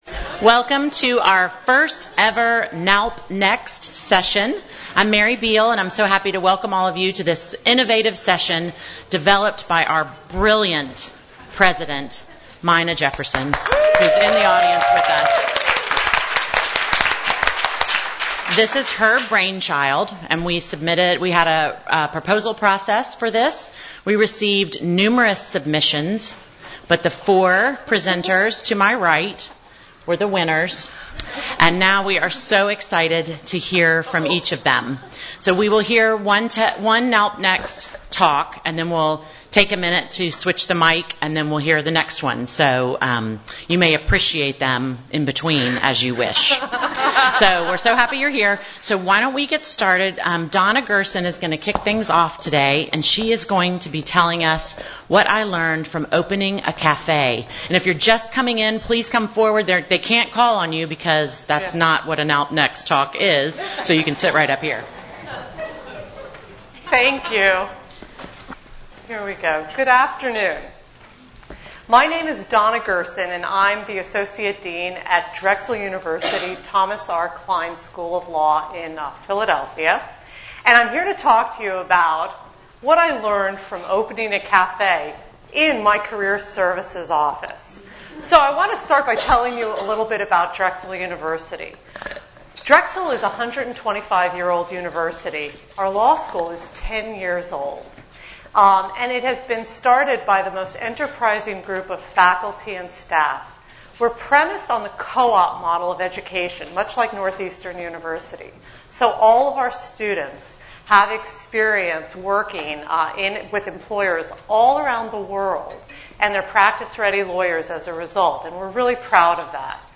Presented at NALP Annual Education Conference, April 2017
The session featured 4 NALP members who gave 10 minute talks on a variety of topics from the gig economy to opening a café.